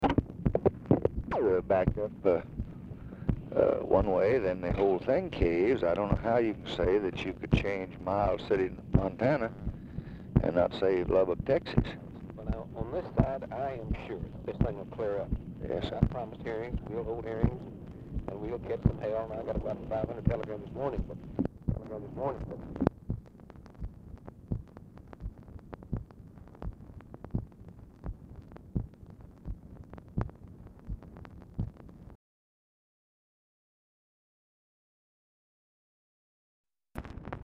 Telephone conversation # 6744, sound recording, LBJ and OLIN "TIGER" TEAGUE?
RECORDING STARTS AFTER CONVERSATION HAS BEGUN AND ENDS BEFORE CONVERSATION IS OVER
Format Dictation belt
Location Of Speaker 1 Mansion, White House, Washington, DC